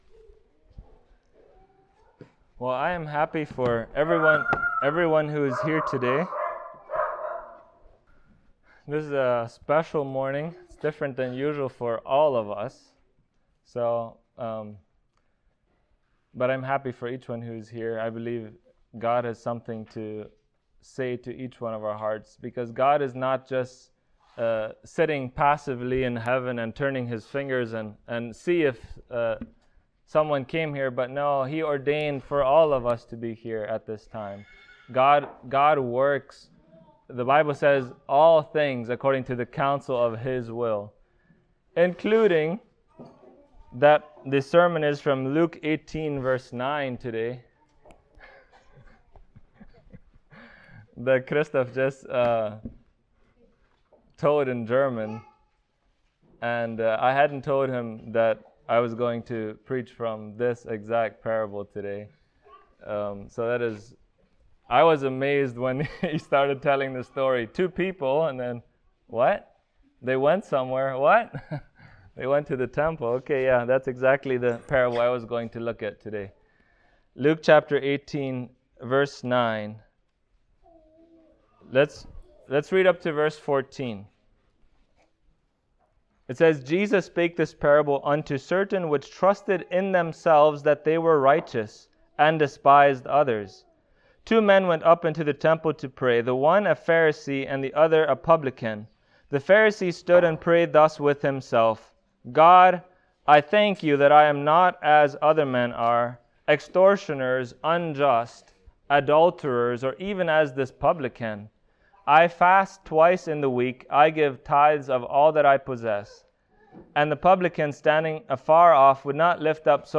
Luke Passage: Luke 18:9-14 Service Type: Sunday Morning Topics